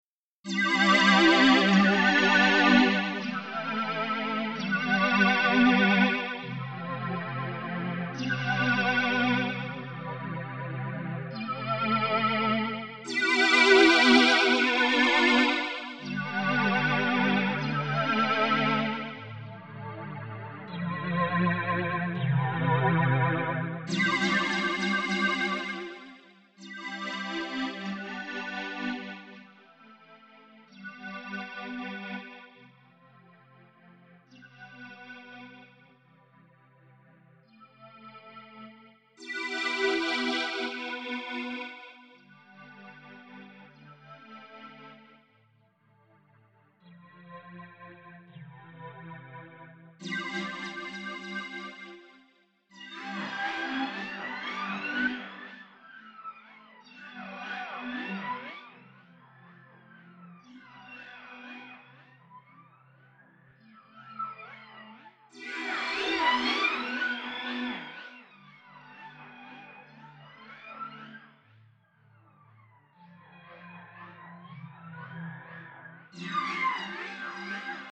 Dabei werden diverse Raumklänge, sowie abgedrehte, synthetische Effekte zur Verfügung gestellt.
Zuerst kommt das Original, ohne Effekt, dann folgen verschiedene Effekte.
RP-Synth.mp3